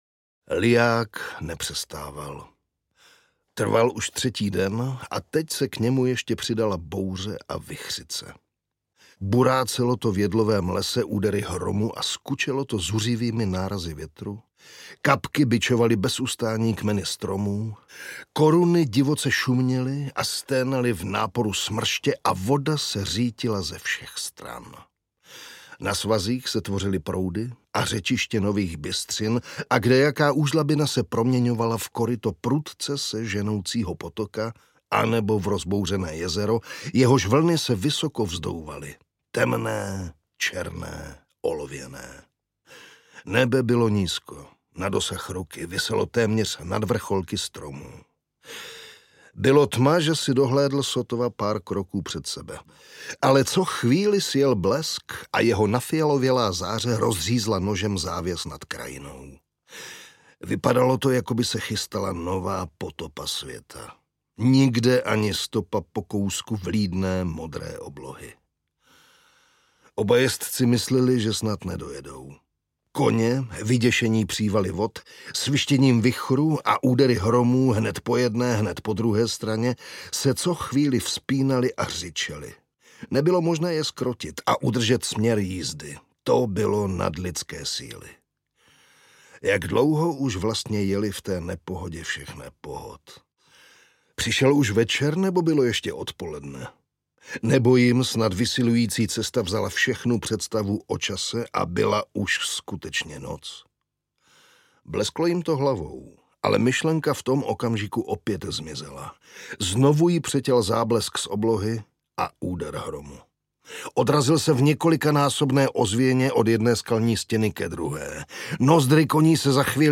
Černý mustang audiokniha
Ukázka z knihy